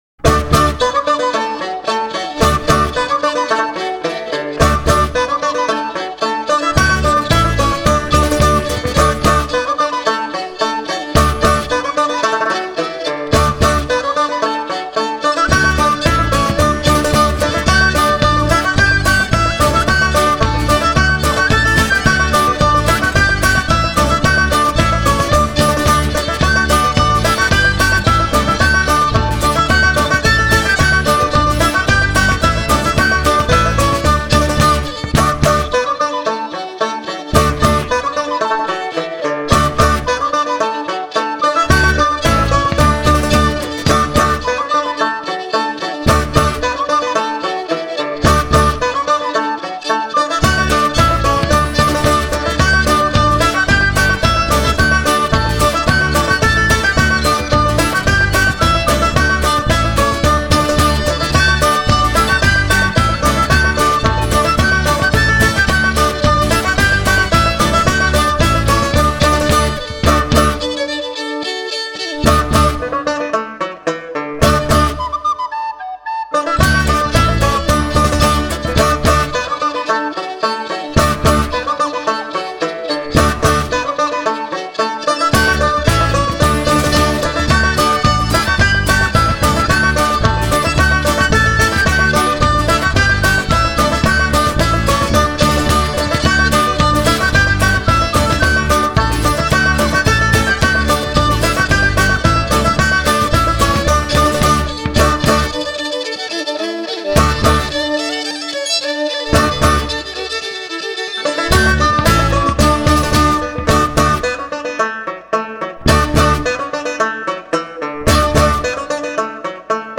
1. Разминка, шотландская